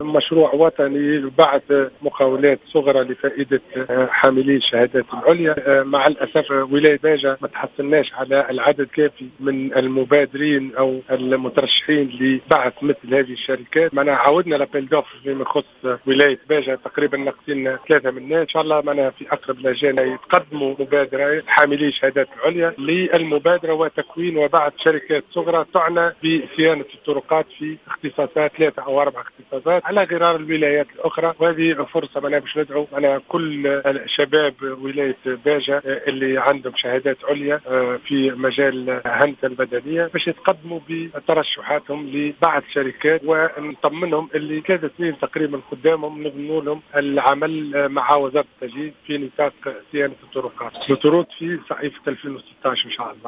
وقال في تصريحات صحفية إن وزارته أعلنت عن طلب عروض لاختيار عدد من المقاولات الصغرى بالجهة متخصصة في صيانة الطرقات السيارة إلا انه لم يتم الحصول على العدد الكافي في هذه المناقصة الجهوية وهناك نقص بنحو 3 شركات.